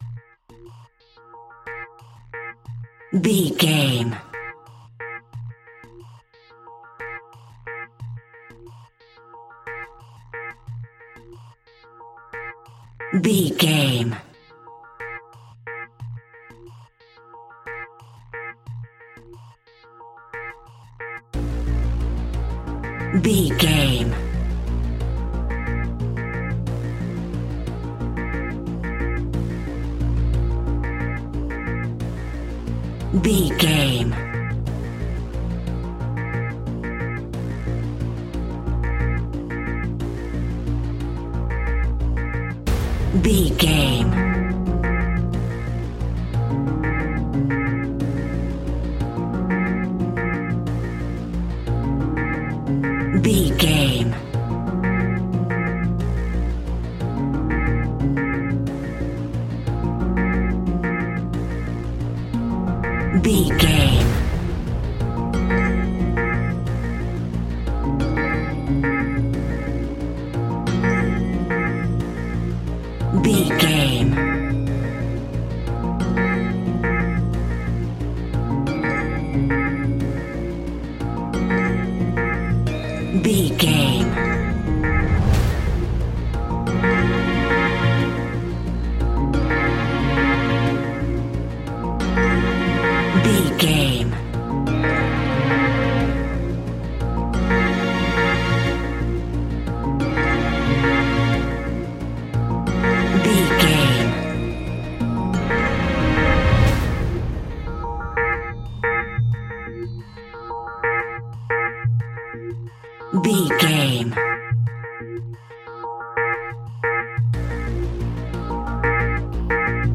Scary Background Industrial Music Alt.
Aeolian/Minor
A♭
ominous
dark
eerie
synthesizer
strings
instrumentals
horror music